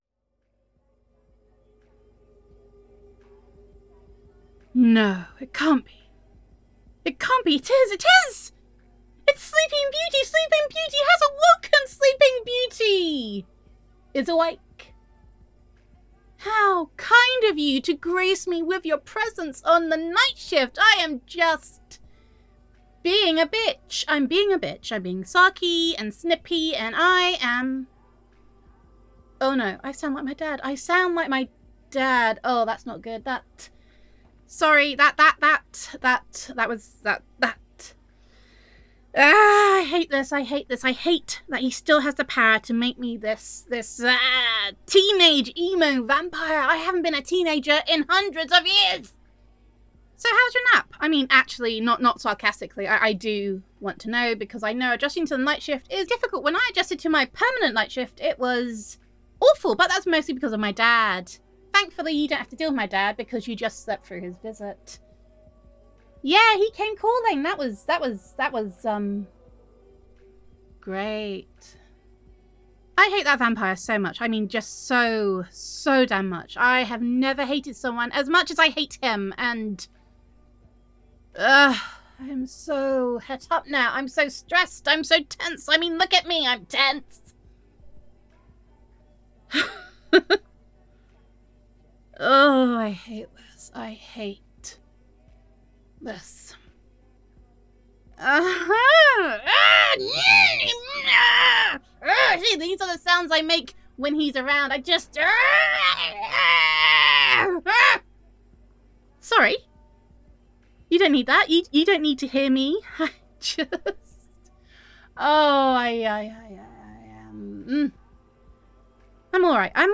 [F4A]
[Slacker Vampire Roleplay]